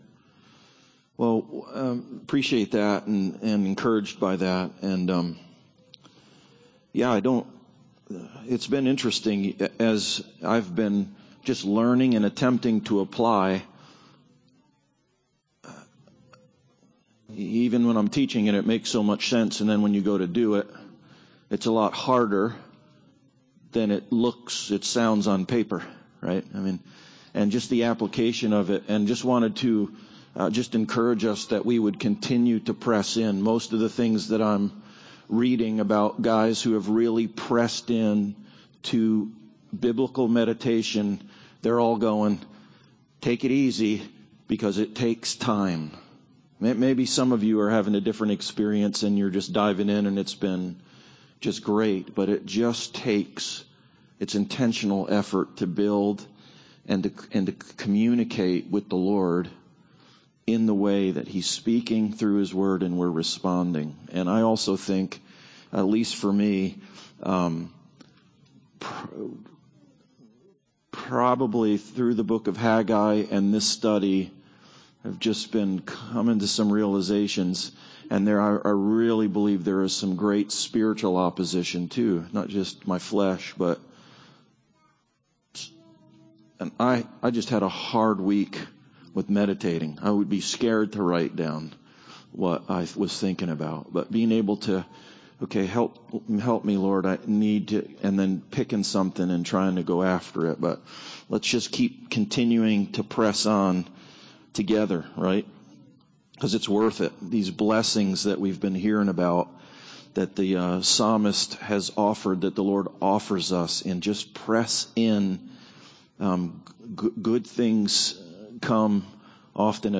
Meditation Service Type: Sunday Service Preacher